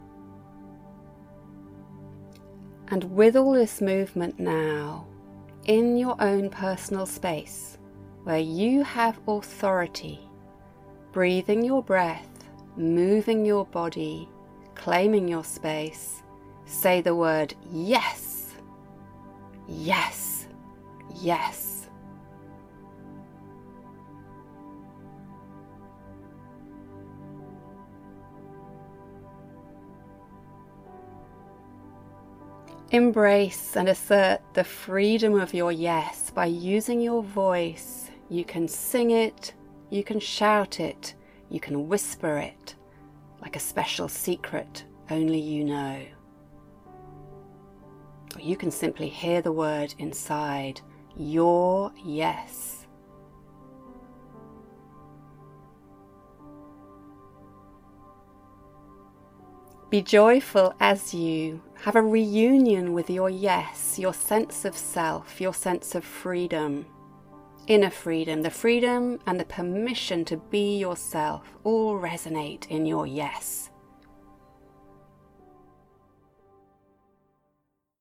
We are vulnerable to overwhelm and taking on draining energies when we say yes to things to which we should be saying no.  In this meditation, which uses movement and voice, claim all the space you need and embrace your “Yes” energies!